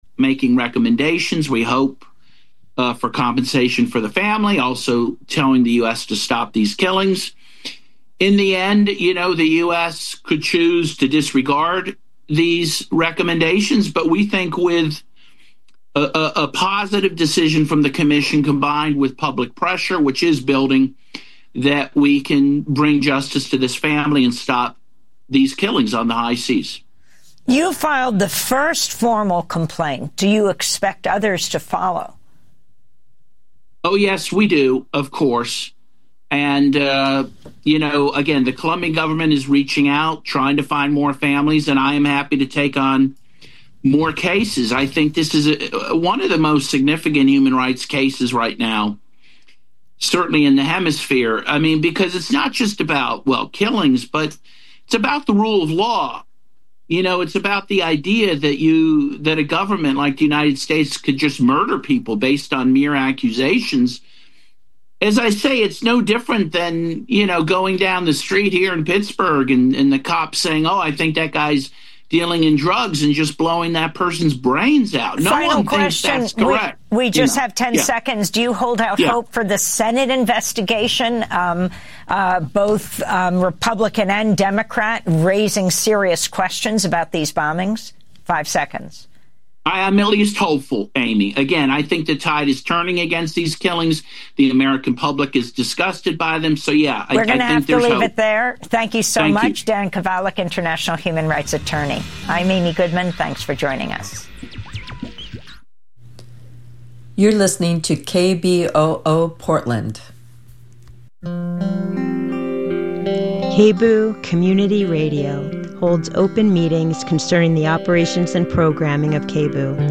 Evening News on 12/05/25